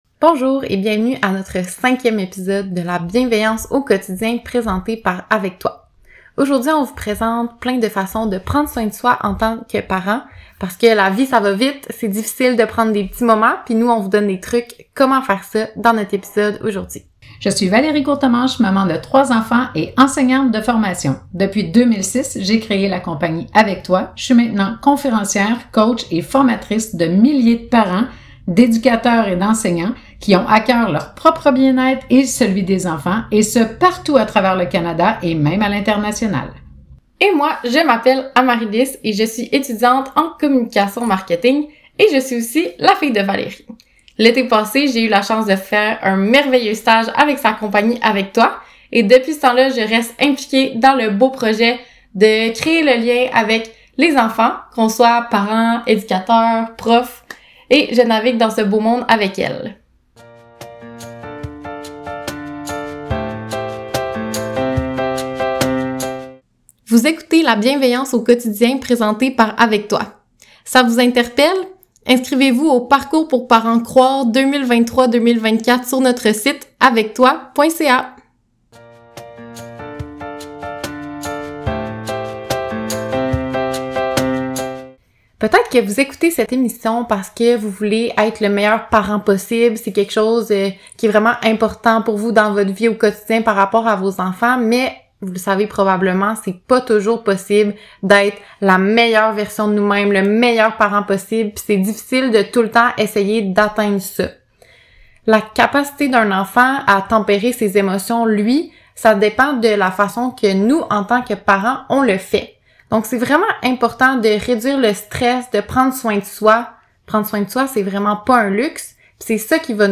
plongent dans une discussion sur l'importance vitale de prendre soin de soi en tant que parent pour mieux prendre soin de ses enfants.